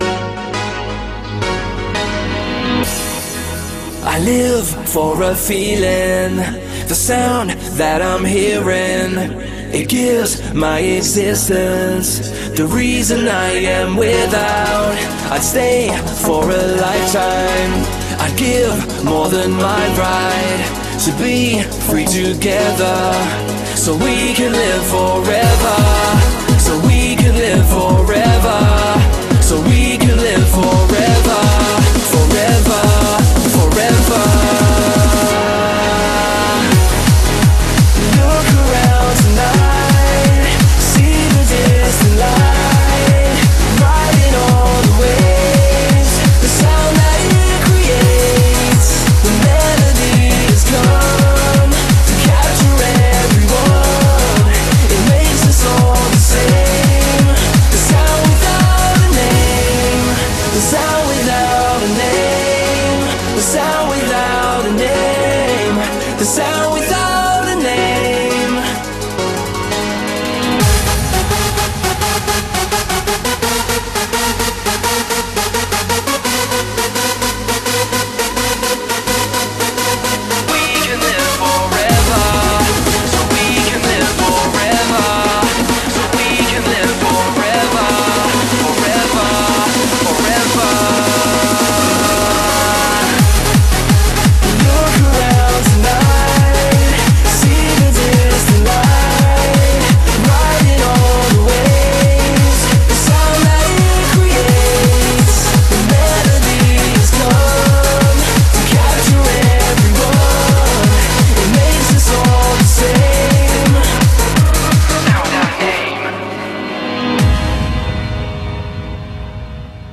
BPM85-170
Comments[UK HARDCORE]